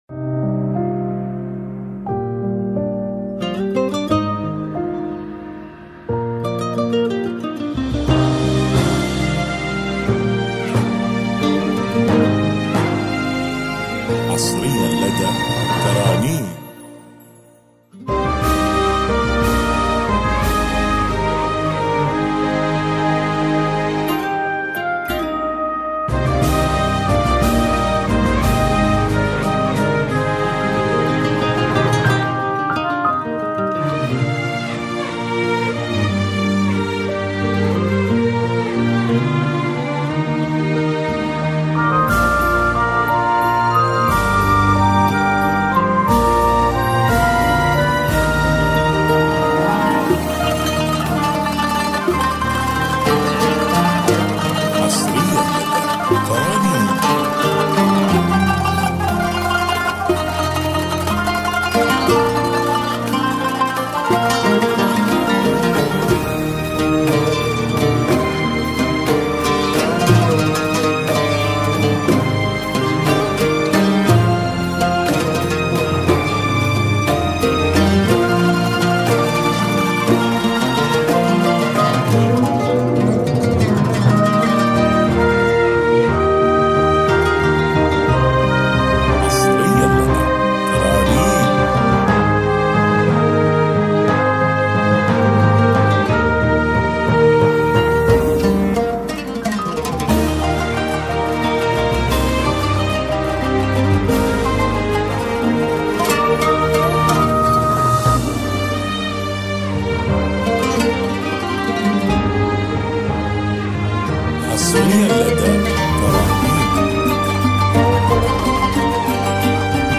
بدون موسيقى